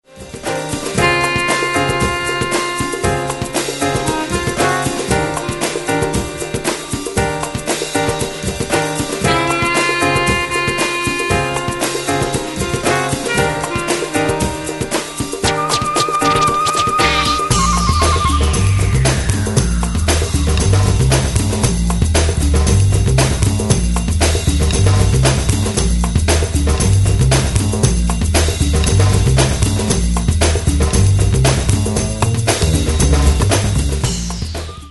downtempo jazz/breaks track